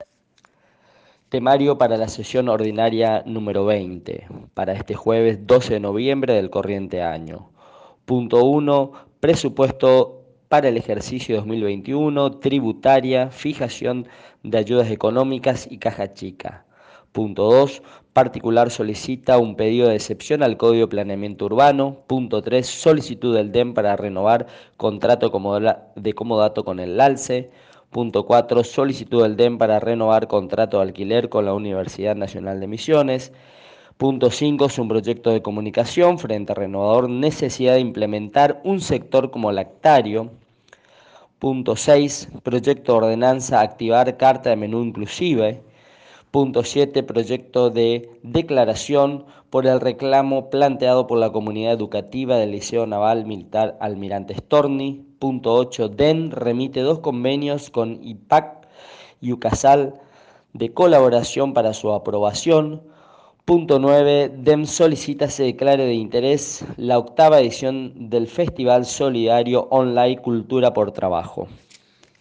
En la nota, el Secretario del Honorable Concejo Deliberante Dr. Jorge Guillermo Kazibrodiuk detalla punto por punto, el temario a ser debatido por los ediles en el día de mañana.